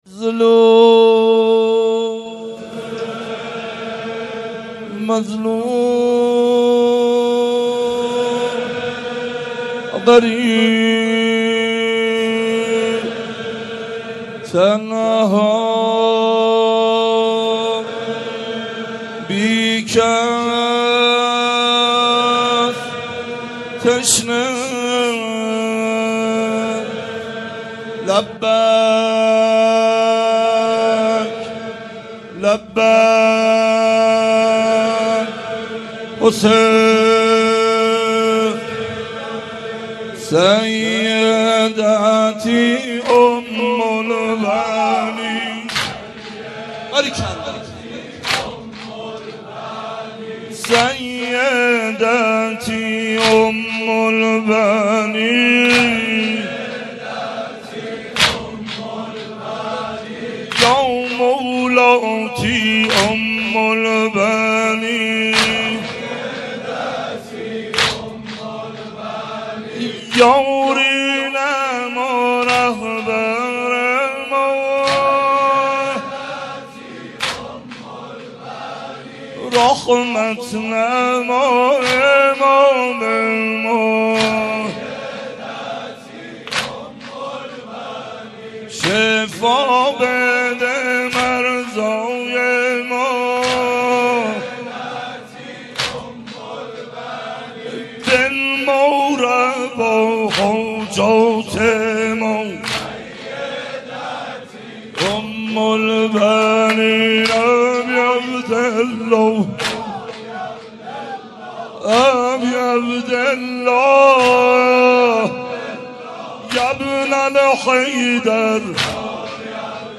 مداح
مناسبت : وفات حضرت ام‌البنین سلام‌الله‌علیها